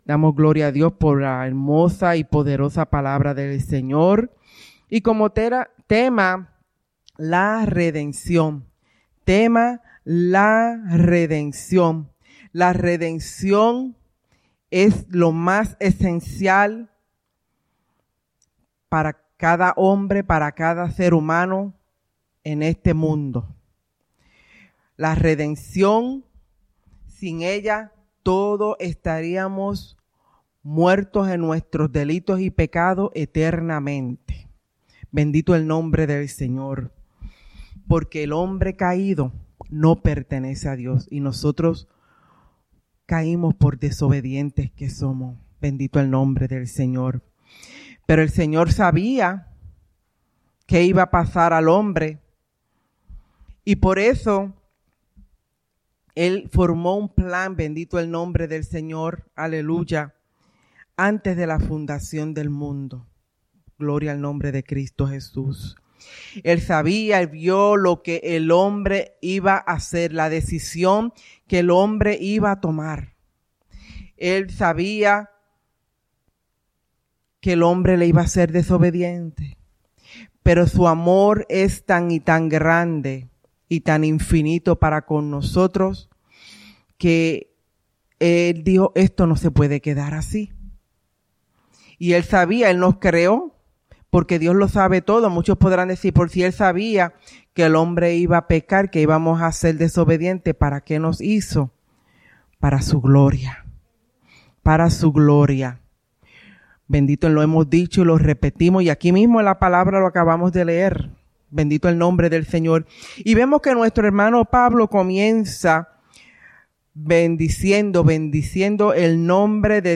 @ Souderton, PA